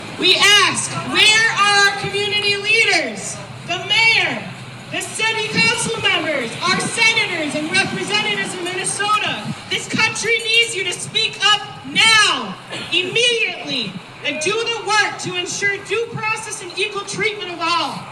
(Learfield News/KWLM/Willmar, MN)  An anti-ICE protest took place in downtown Willmar at 1pm Tuesday.